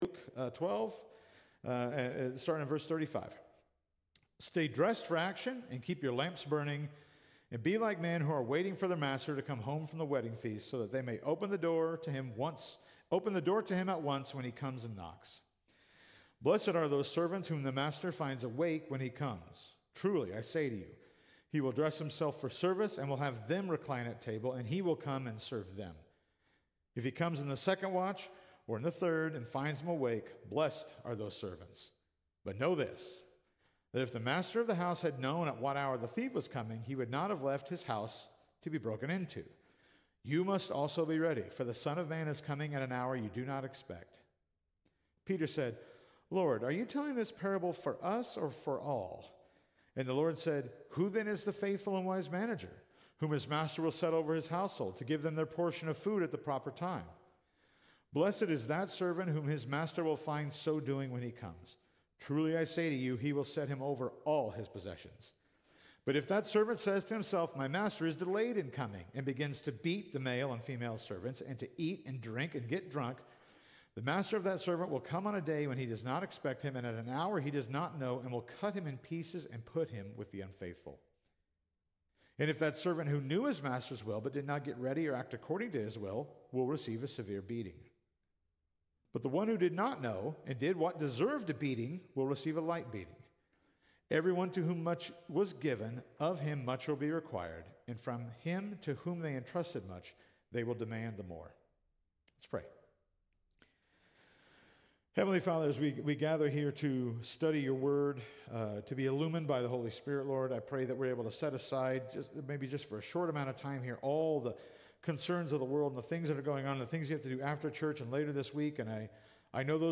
Sermons by Calvary Heights Baptist Church